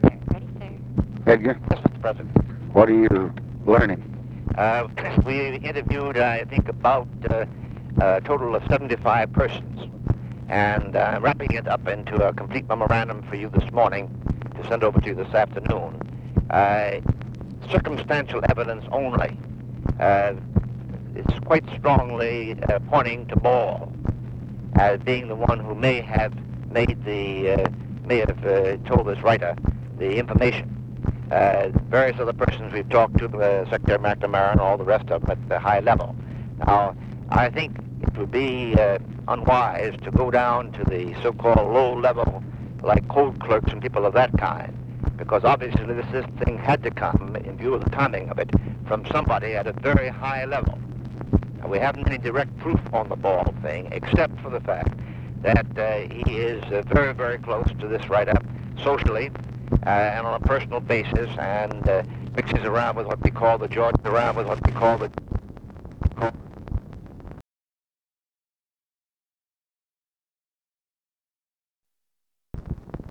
Conversation with J. EDGAR HOOVER, June 29, 1966
Secret White House Tapes